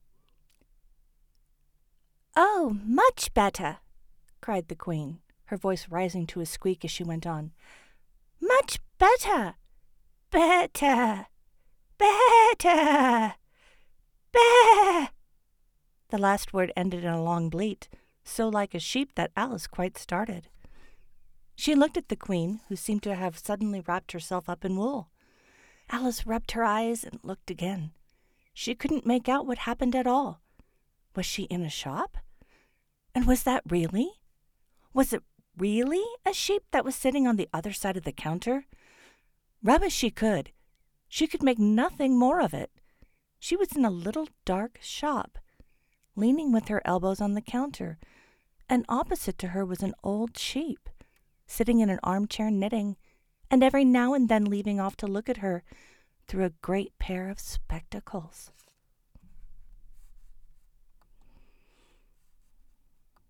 Talent Demos
Book-Sample-Through-The-Looking-Glass.mp3